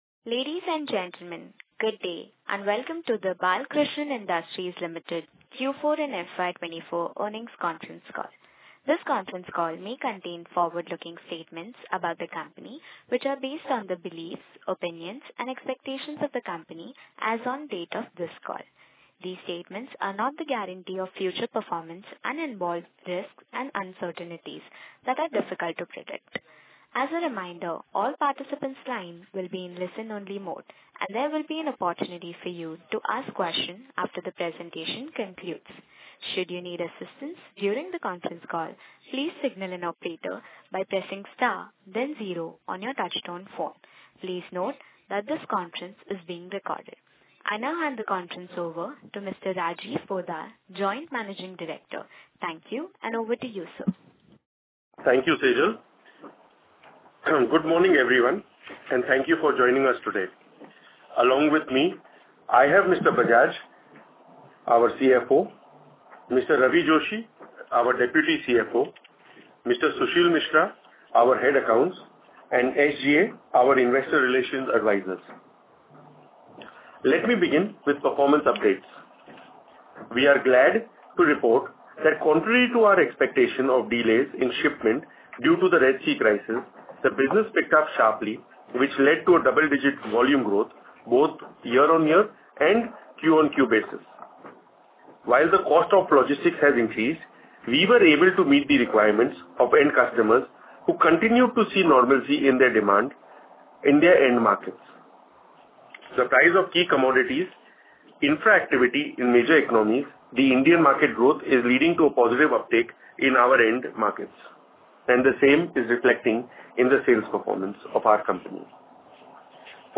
Audio recordings of conference Call dated 18th May, 2024